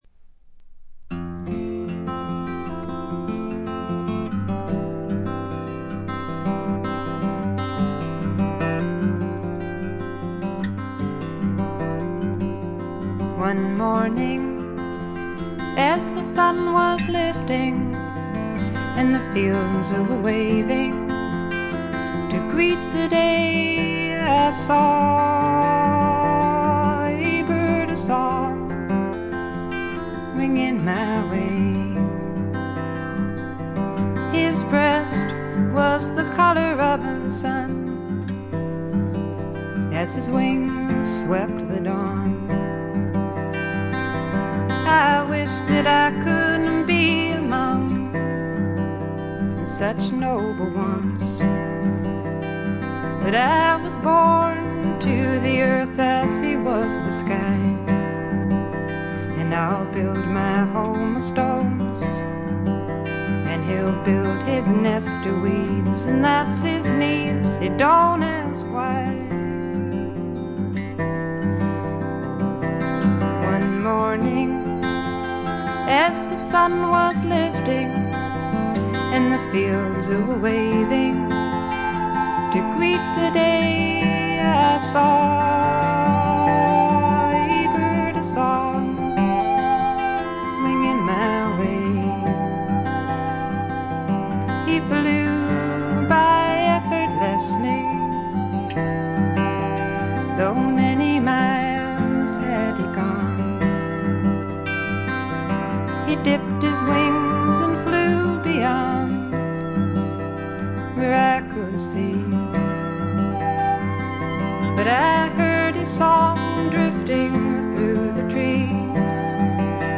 女性シンガー・ソングライター、フィメール・フォーク好きには必聴／必携かと思います。
試聴曲は現品からの取り込み音源です。
Recorded at Bearsville Sound Studios, Woodstock, N.Y.
Vocals, Acoustic Guitar